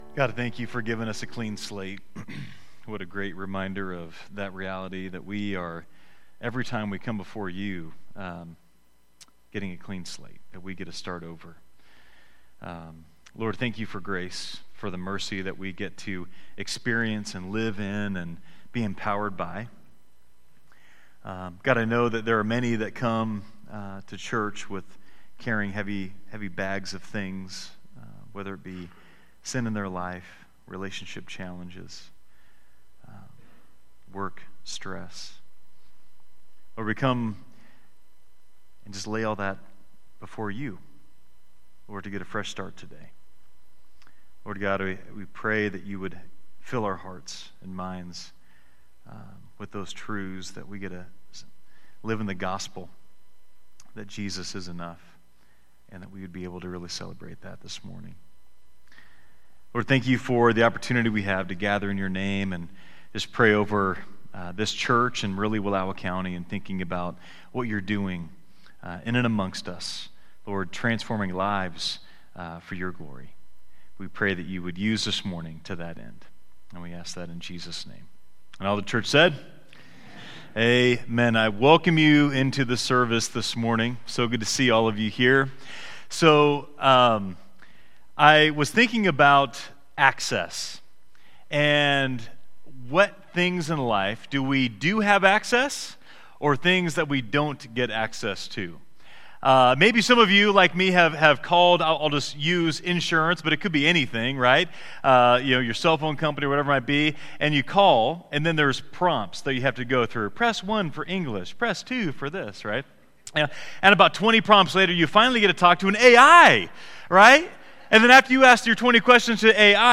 Download Download Reference Matthew 7: 7 - 12 Sermon Notes 4.